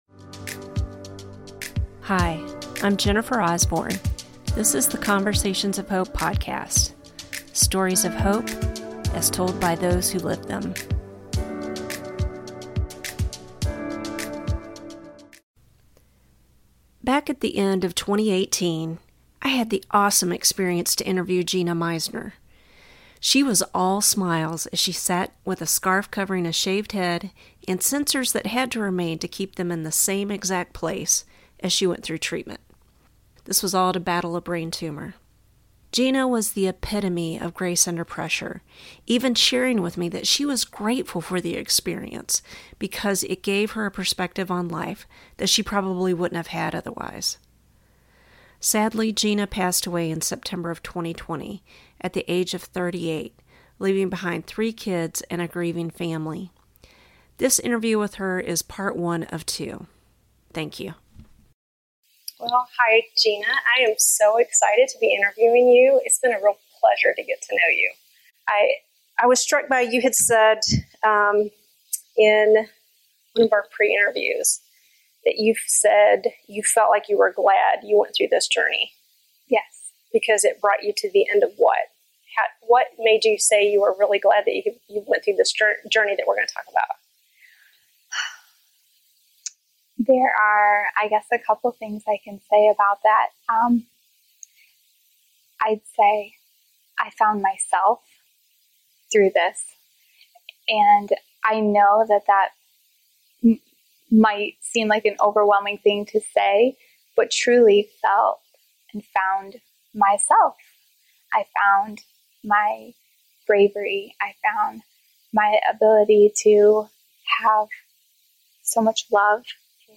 This part one of our conversation.